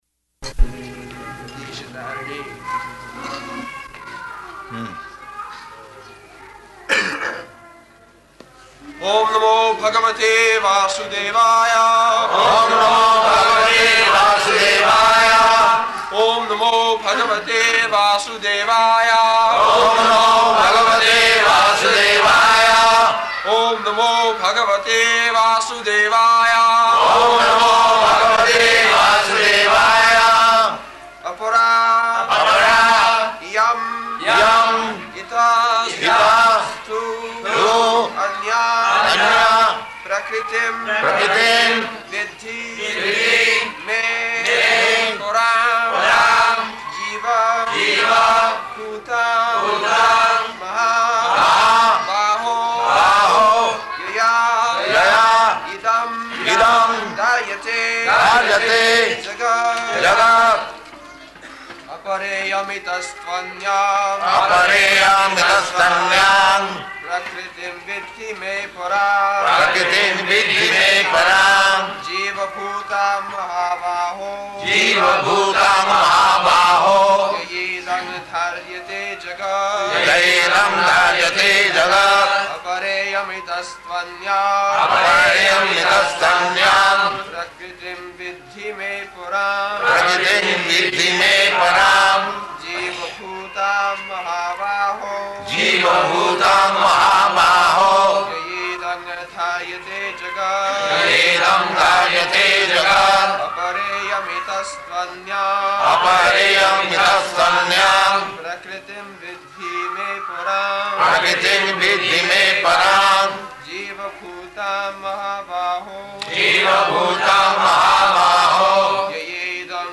February 20th 1974 Location: Bombay Audio file
[loud Indian music in background throughout]
[music grows louder] Hmm.